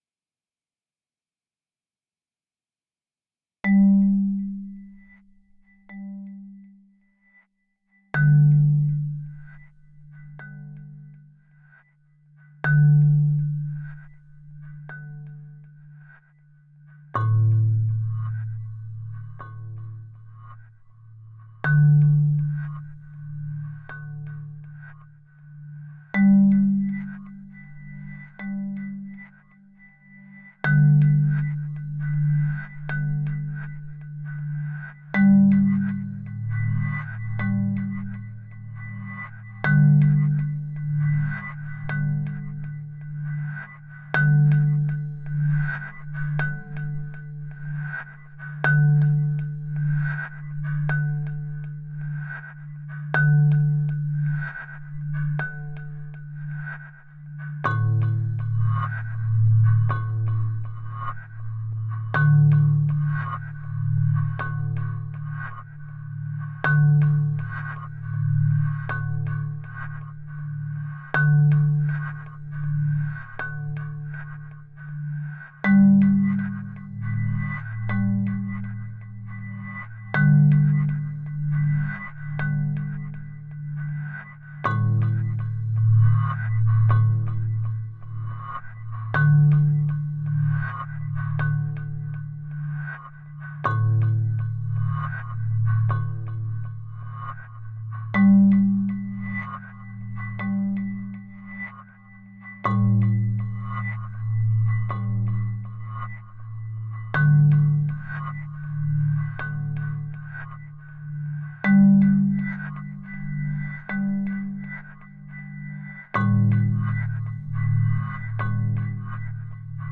Out with the drones, in with the lush pads and synth lines.